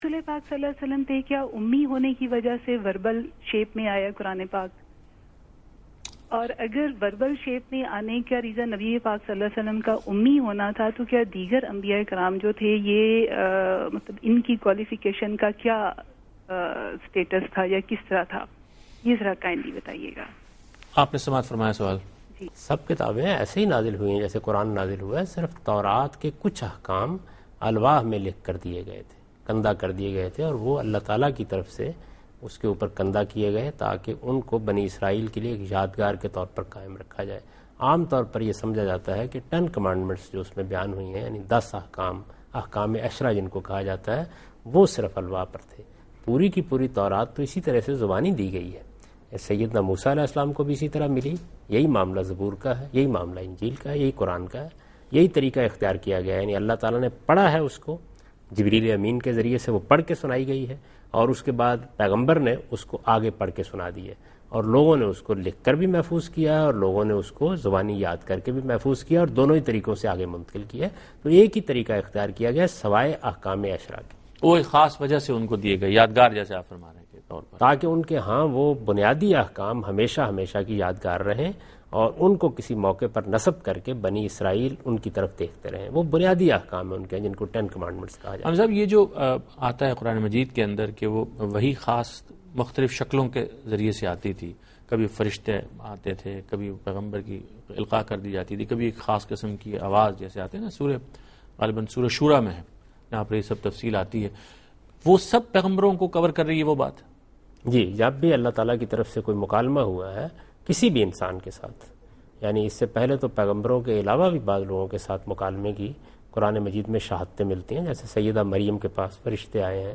Answer to a Question by Javed Ahmad Ghamidi during a talk show "Deen o Danish" on Duny News TV
دنیا نیوز کے پروگرام دین و دانش میں جاوید احمد غامدی ”انبیاء کی تعلیمات“ سے متعلق ایک سوال کا جواب دے رہے ہیں